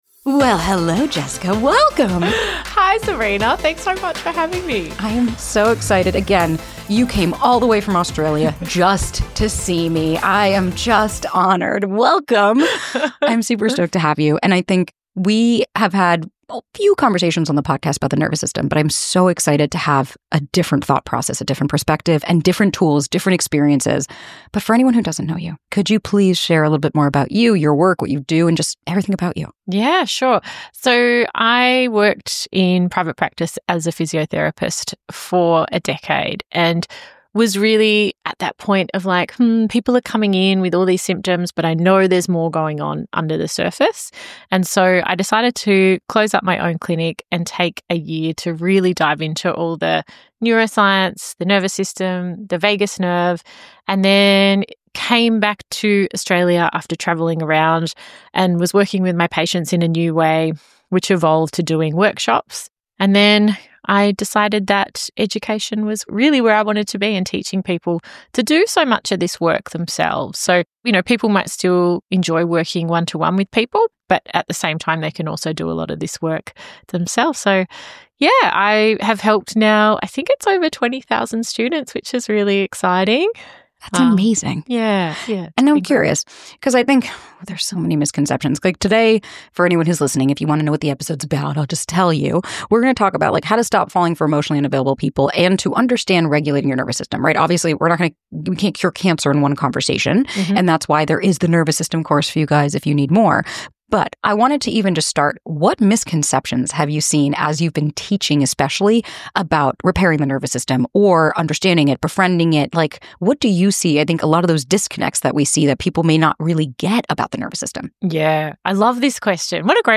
Whether you're constantly scanning for red flags or feel overwhelmed by connection, this conversation breaks down the difference between true intuition and trauma-informed reactions.